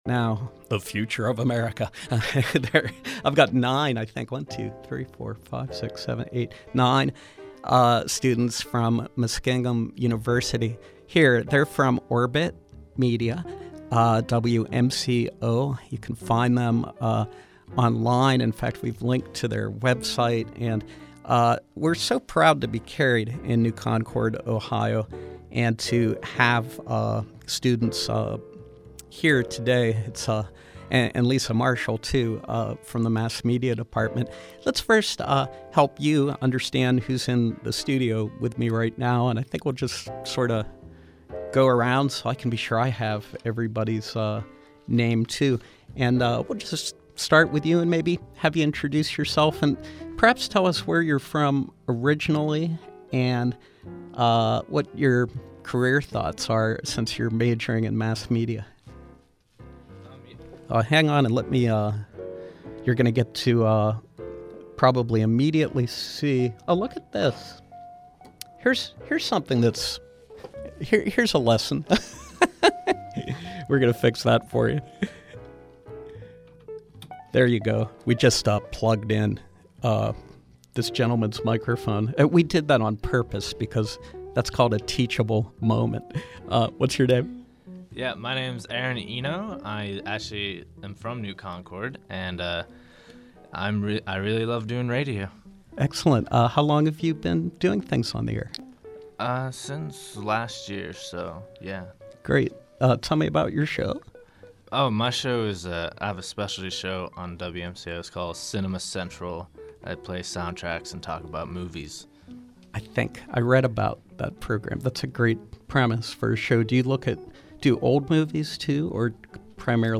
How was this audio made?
From 11/14/2015: Students of Muskingum University’s media group, Orbit Media Online, which operates WMCO 90.7FM, visit the studio and discuss media coverage ethics.